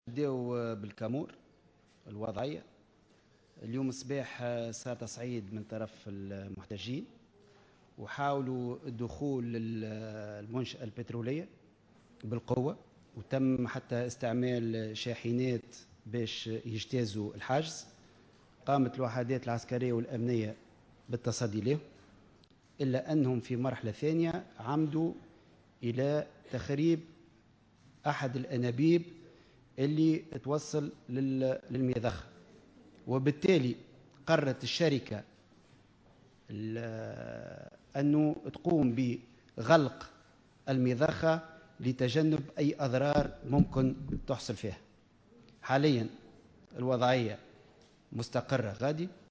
Lors d'une conférence de presse tenue ce lundi, Oueslati a précisé que la société pétrolière en question a décidé, suite aux actes de violence, de fermer le site de production, par mesures de précaution.